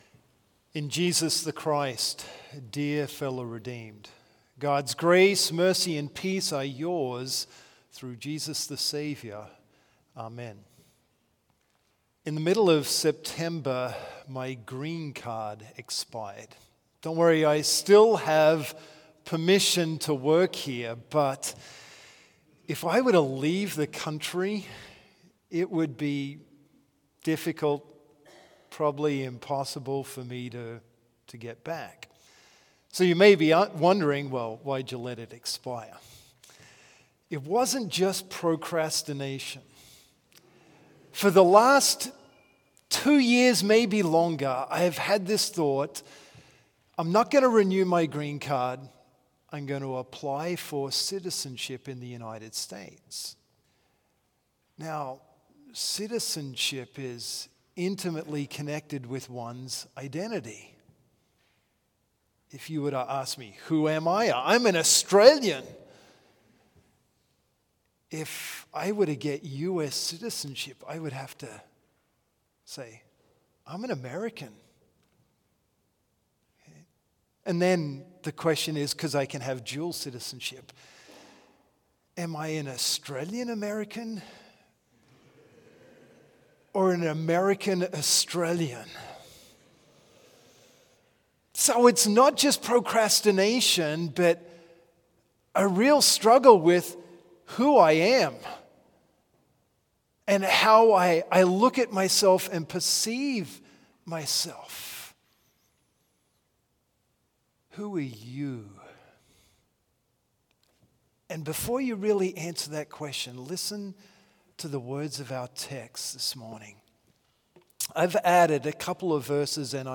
Complete service audio for Chapel - Thursday, November 7, 2024